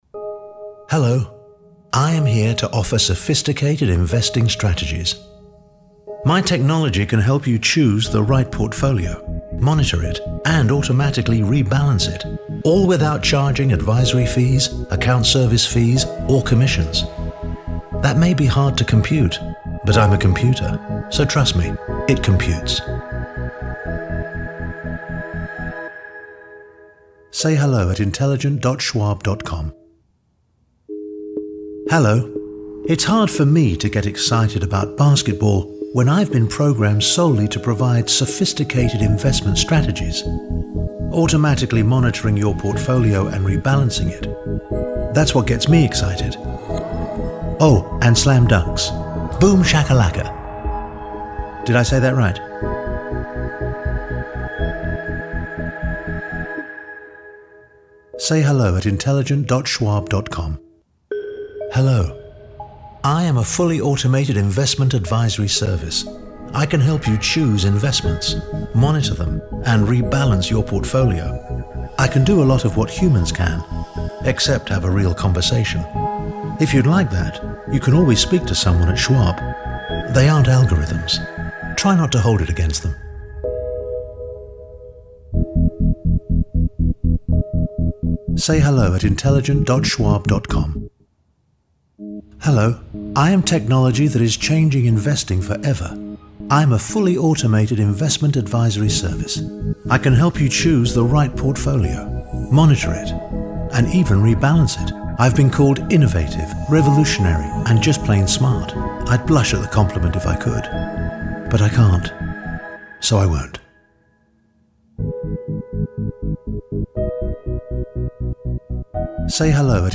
Commercial
Alfred Molina (Commercial Demo).mp3